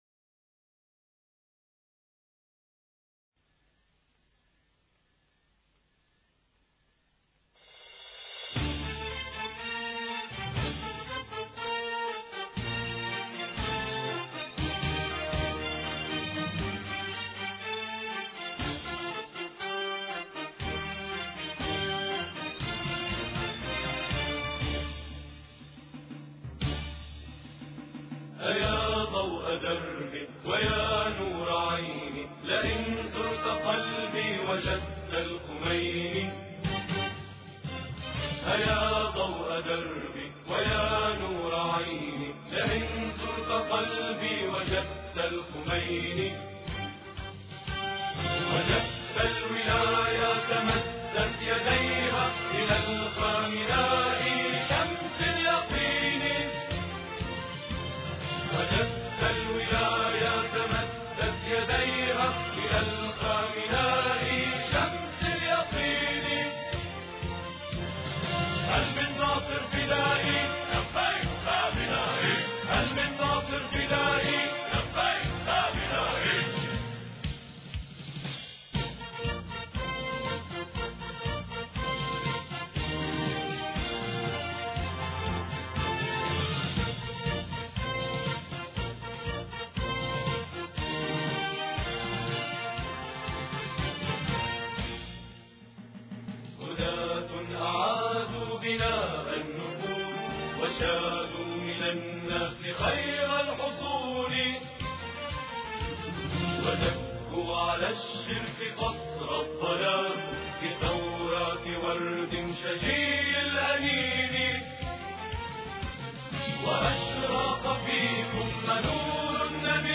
لبيك خامنائي الثلاثاء 6 فبراير 2007 - 00:00 بتوقيت طهران تنزيل الحماسية شاركوا هذا الخبر مع أصدقائكم ذات صلة الاقصى شد الرحلة أيها السائل عني من أنا..